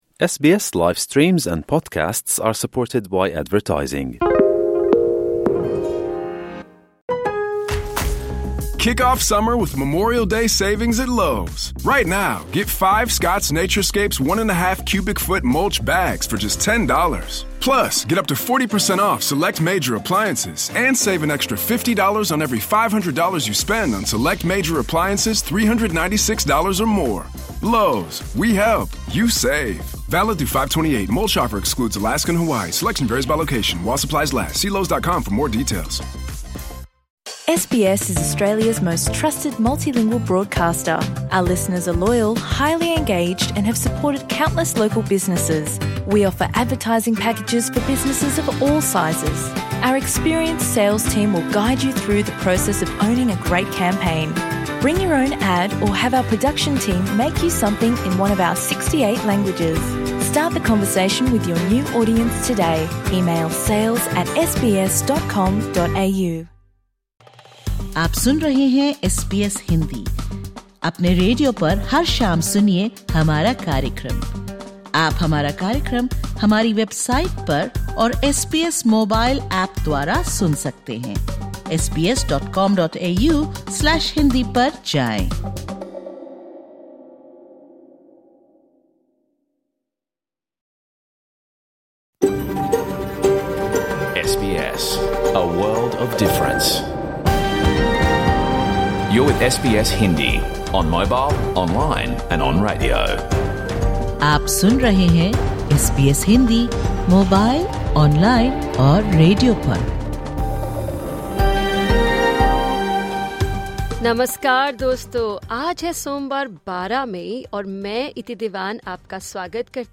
In this edition of the SBS Hindi radio program, tune in for an exclusive interview with celebrity Indian chef Sanjeev Kapoor as he shares his favourite spots in Australia and insights on the future of cooking. On International Nurses Day, Australian nurses speak candidly about the challenges they face on the frontlines. We also hear expert analysis on the stability of the India-Pakistan ceasefire, and cover the political shake-up in Canberra following Labor’s landslide election victory.